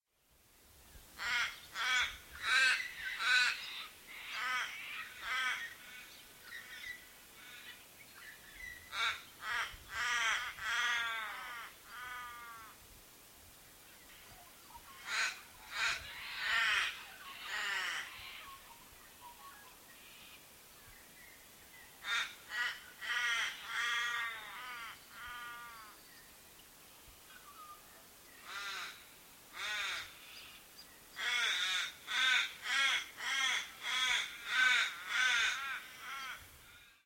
The Little’s call is mostly a series of harsh level lower-pitched caws. Click on the audio bars below to hear locally recorded calls.
little-raven.mp3